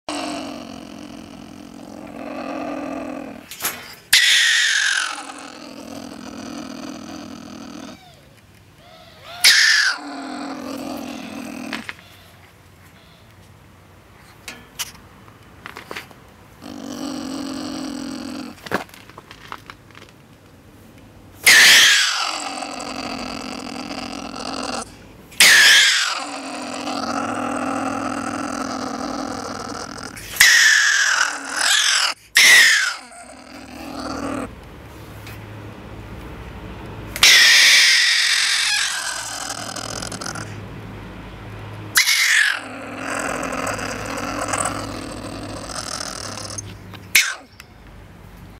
Neszeik sokféle, félelmet keltő elemből szövődnek össze: kaparászás, kaffogás, morgás, vinnyogás kusza keveréke.
Nyest-fogságban.MOV.mp3